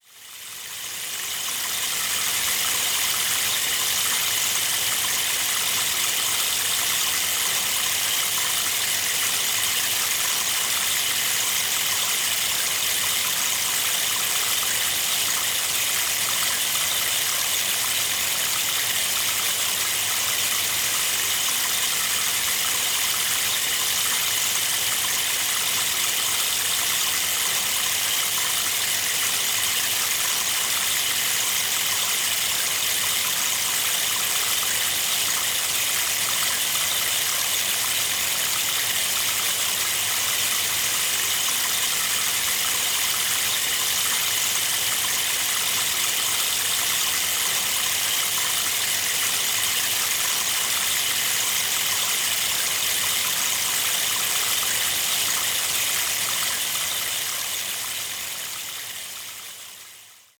Der Meinl Sonic Energy Rainmaker ist aus hochwertigem, lackiertem Holz gefertigt und erzeugt einen beruhigenden, natürlichen Regensound, der tief…
Seine sanften, fließenden Klänge machen ihn zum perfekten Klanghintergrund für Klangbäder, Klangreisen, Yoga-Sessions und Meditationen, wobei gleichzeitig andere Instrumente gespielt werden können.